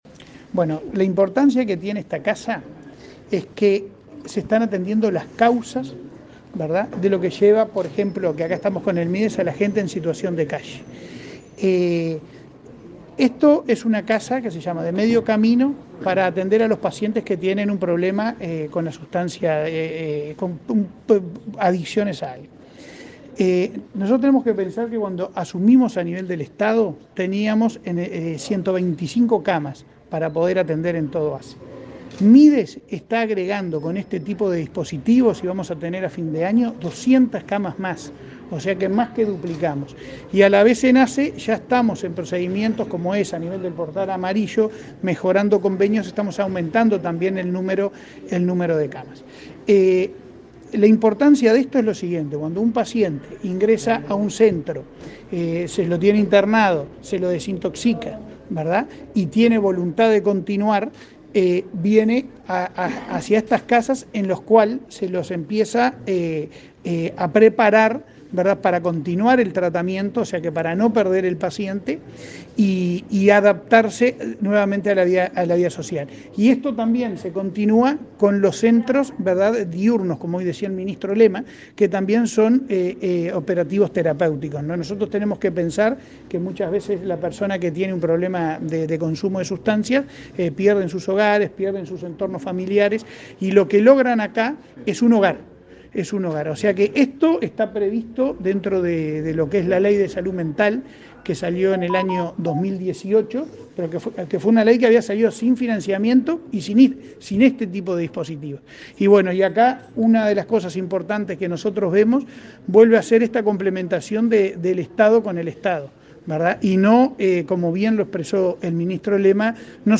Declaraciones del presidente de ASSE, Leonardo Cipriani
Declaraciones del presidente de ASSE, Leonardo Cipriani 21/07/2022 Compartir Facebook X Copiar enlace WhatsApp LinkedIn El ministro de Desarrollo Social, Martín Lema, y el presidente de la Administración de los Servicios de Salud del Estado (ASSE), Leonardo Cipriani, participaron de la inauguración de una casa denominada "de medio camino", instalada en Montevideo por el ministerio y ASSE. Luego, Cipriani dialogó con la prensa.